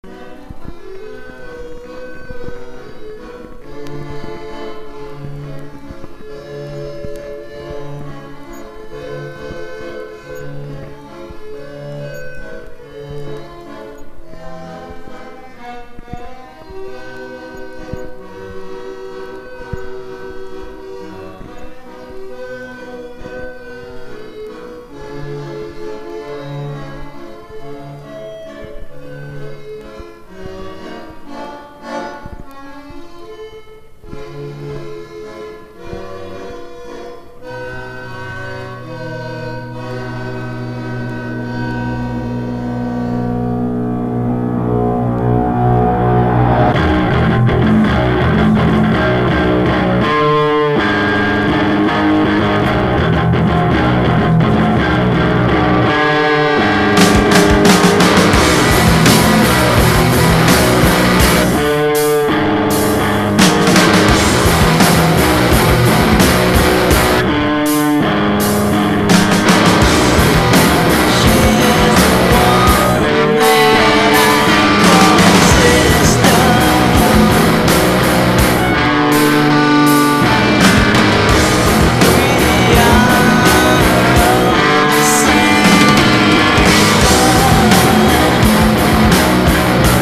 1. 90'S ROCK >
ヘヴィー＆ノイジーなギター・サウンドと、徹底的にスローなビート、元祖ストーナー的全7曲収録。
ALTERNATIVE / GRUNGE